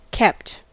Ääntäminen
Ääntäminen US Tuntematon aksentti: IPA : /ˈkɛpt/ Haettu sana löytyi näillä lähdekielillä: englanti Käännöksiä ei löytynyt valitulle kohdekielelle.